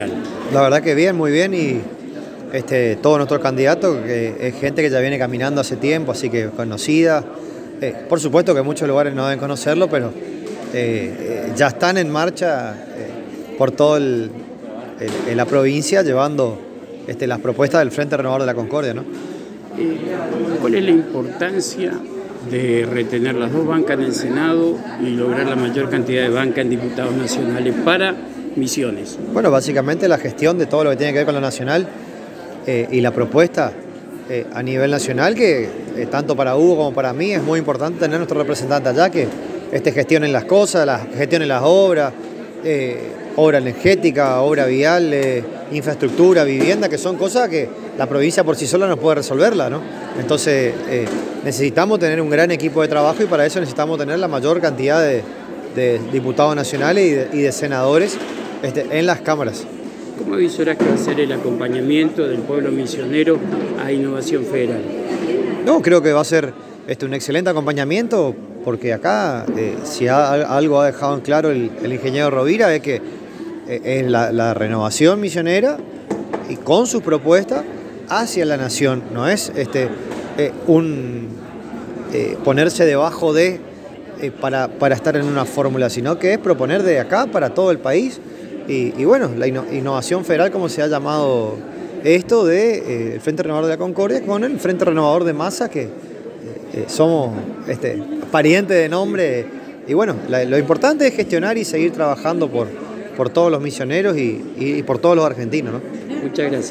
El Vicegobernador electo, Lucas Romero Spinelli, en declaraciones exclusivas a la ANG expresó que en la recorrida hecha por los municipios ve la gran aceptación del pueblo misionero a los candidatos a Legisladores Nacionales del Frente Renovador expresada en Innovación Federal, augurando un nuevo triunfo en las próximas elecciones y resaltando que para la próxima gestión encabezada por Hugo Passalacqua y secundada por él es muy importante obtener las dos bancas en el Senado y la mayoría de Diputados Nacionales.